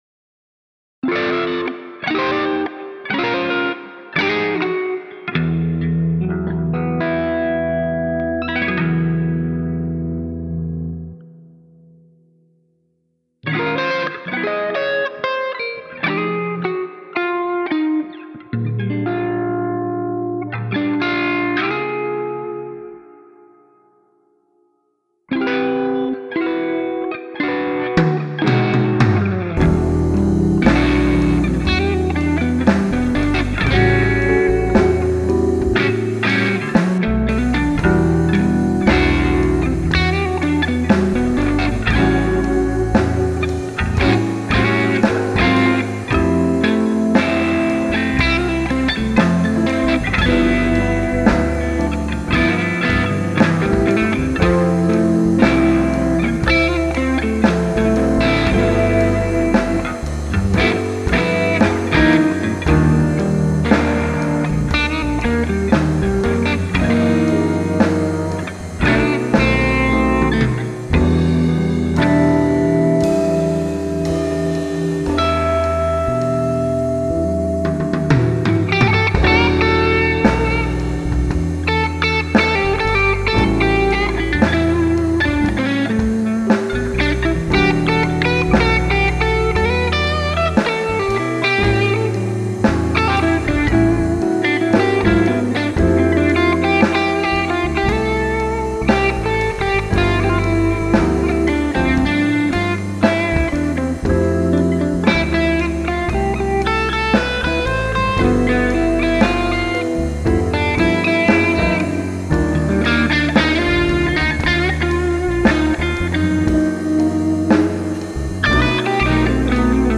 drums
bass
instrumental tune